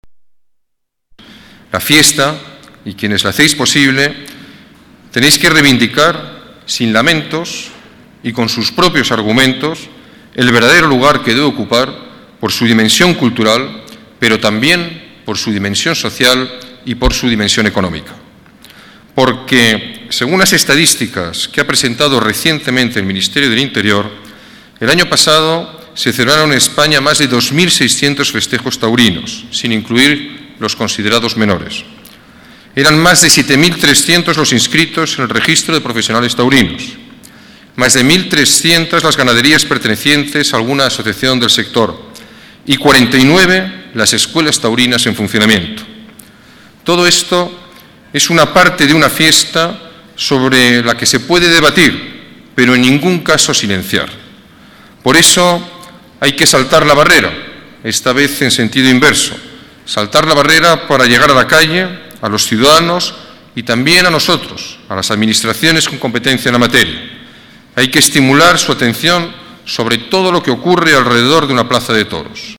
Nueva ventana:Declaraciones del alcalde durante la entrega de los trofeos taurinos de la Feria de San Isidro 2007